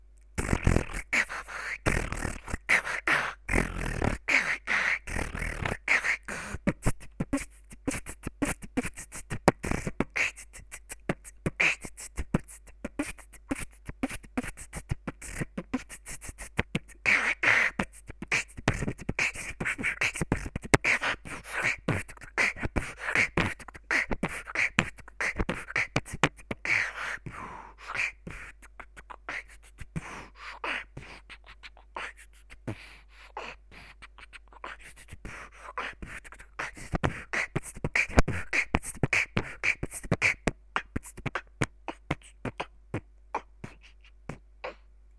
(ускоряемся)B tt bKch tt Brr Bt bKch tt
Дальше под шум радио: B Kch b tktkt Kch ttt [x4, под конец выходим из шума]
B K B ttB K [x2, замедляясь и под шум]
четкость хай - хетов отрбатывай и погромче хендклеп делай)